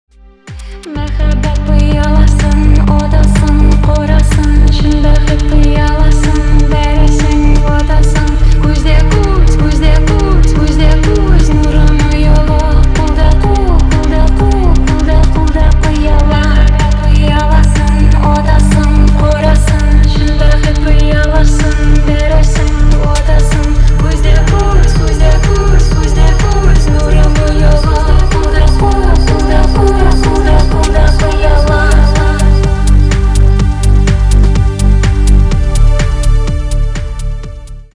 татарские